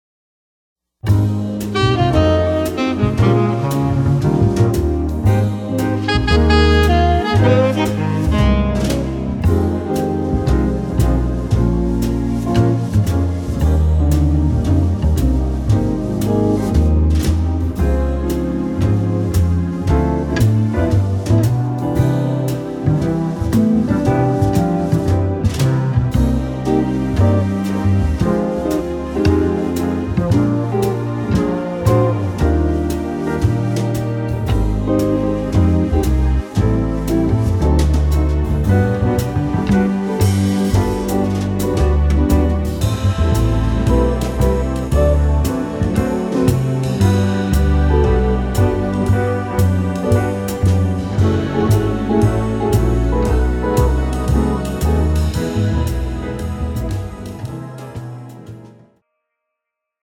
jazz ballad style
tempo 115 bpm
male backing track
This track is in medium tempo jazz ballad style.